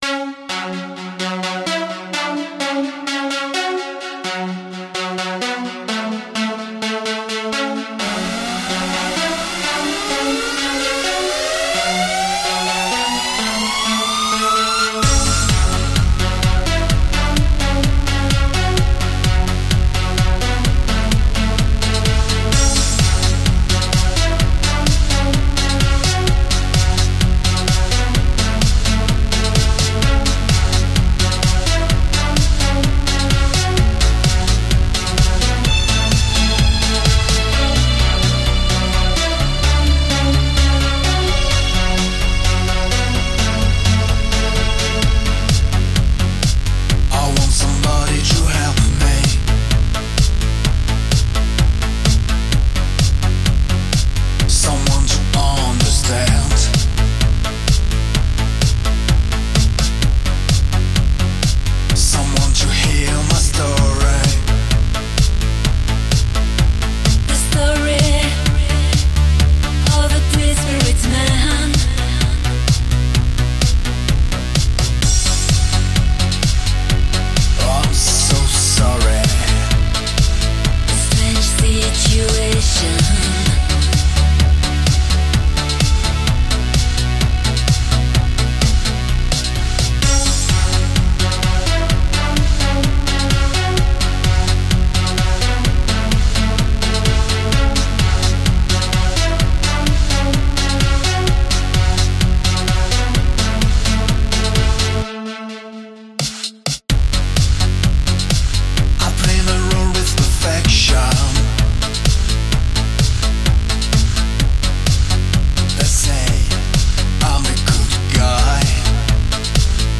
The Anix interview
Originally broadcast on Dark Essence Radio @ 4ZZZ FM Brisbane – 13/8/2011 Download this podcast